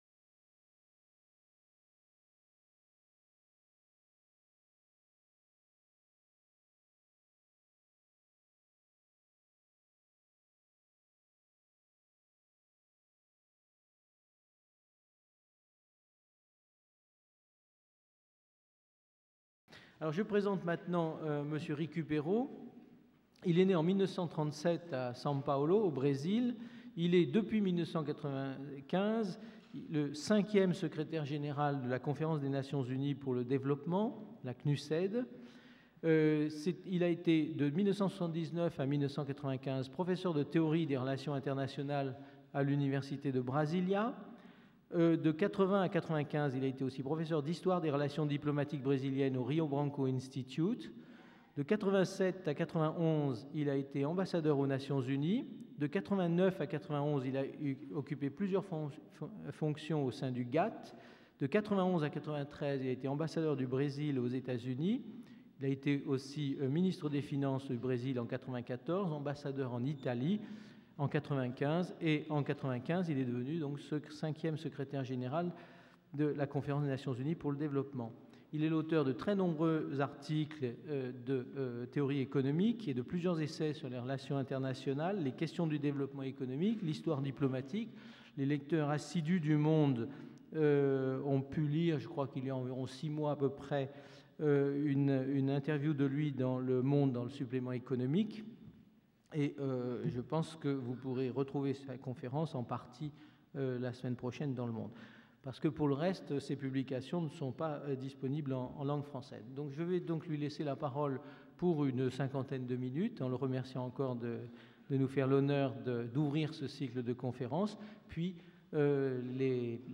Conférence donnée le 28 mars 2002 par Rubens RICUPERO.